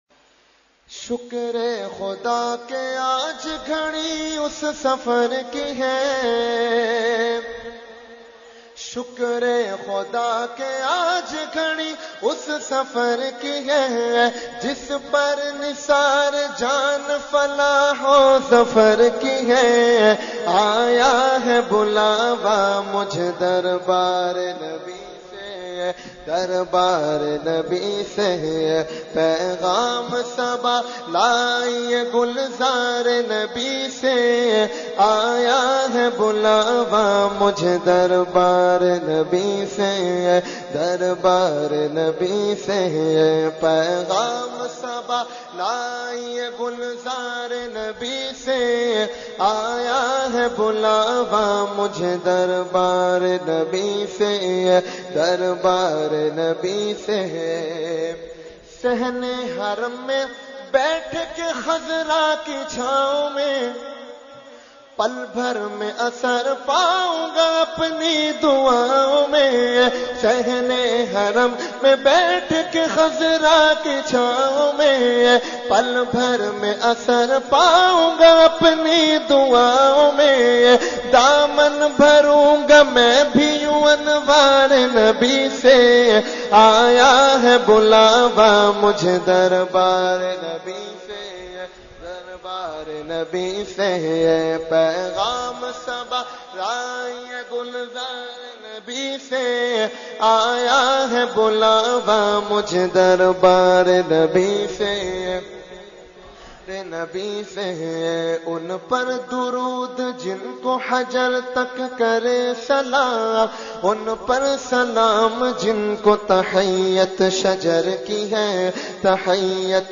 Category : Naat | Language : UrduEvent : Khatmul Quran 2018